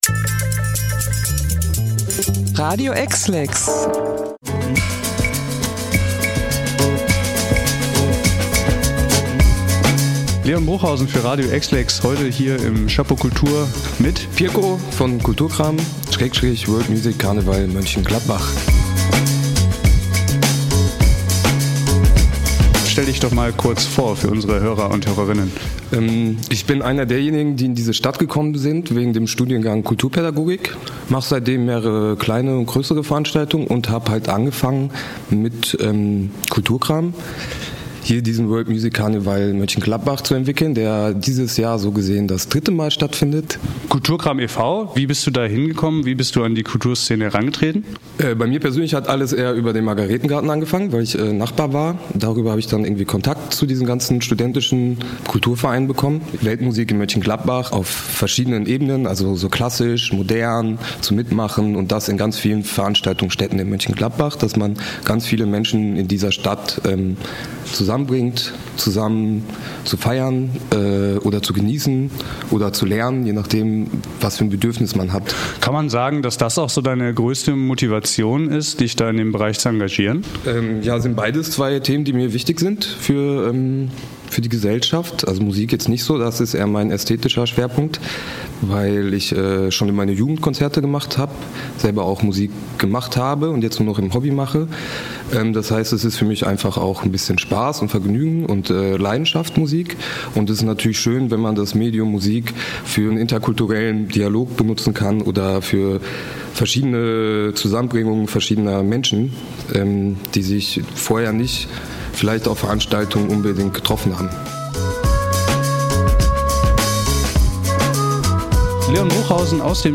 Interview-World-Music-Carneval-LB-TB.mp3